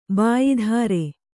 ♪ bāyi dhāre